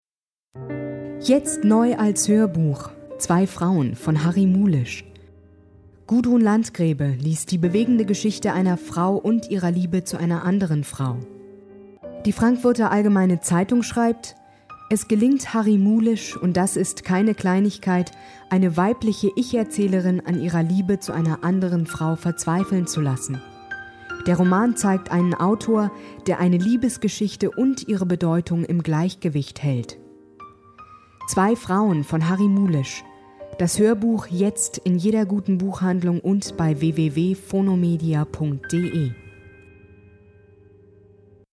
Sprechprobe: Werbung (Muttersprache):